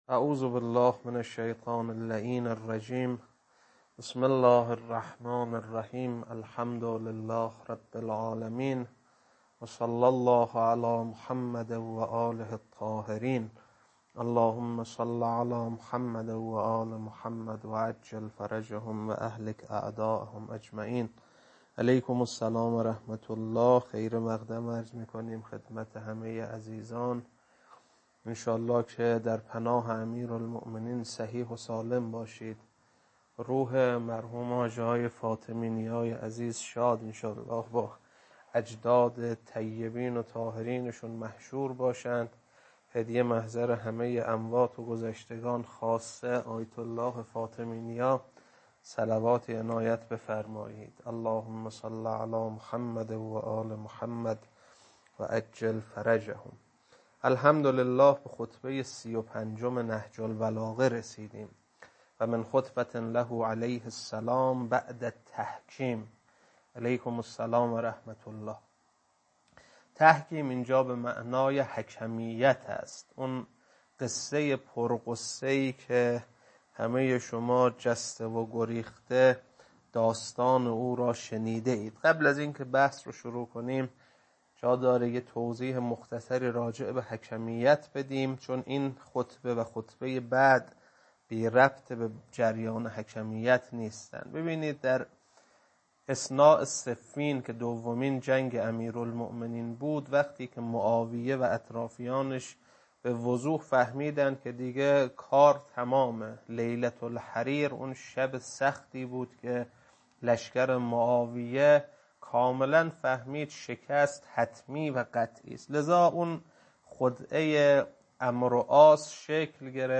خطبه-35.mp3